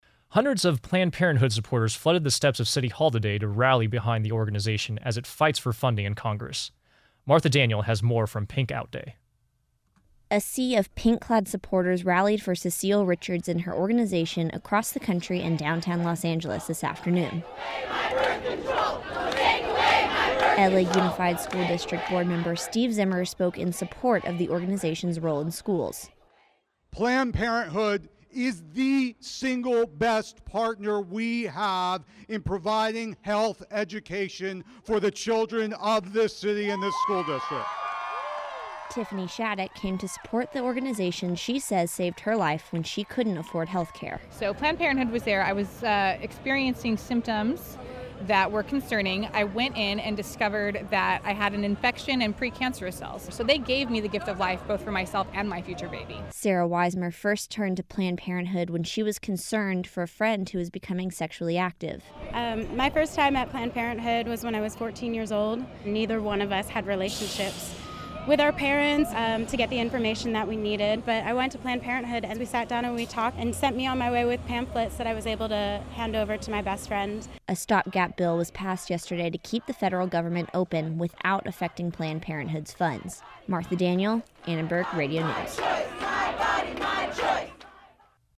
PlannedParenthoodRally.mp3